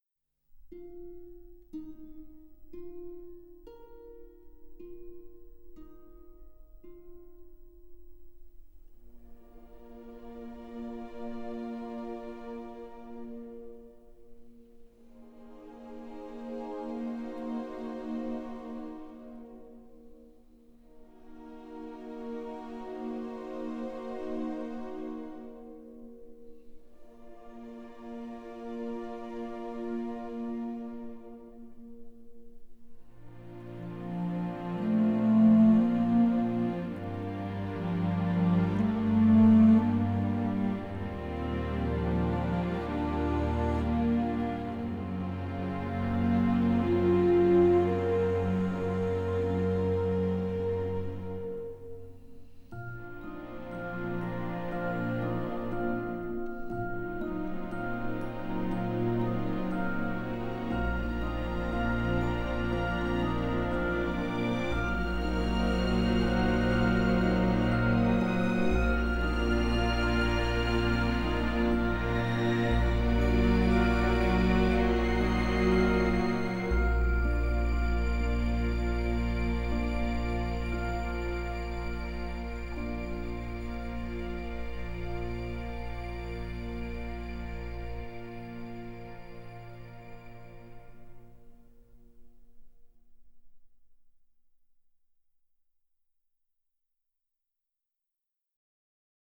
bittersweet finale